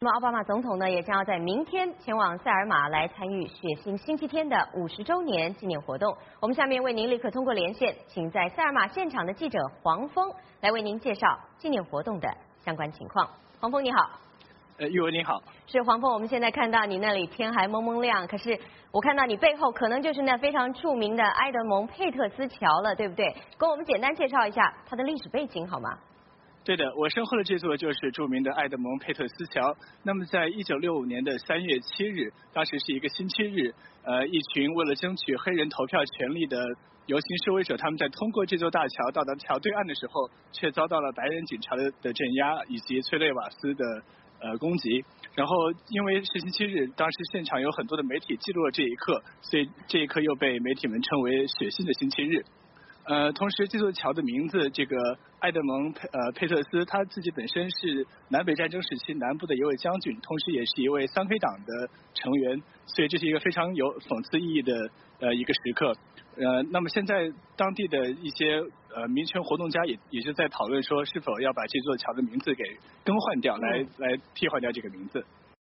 VOA连线：塞尔玛游行50年，奥巴马将出席纪念活动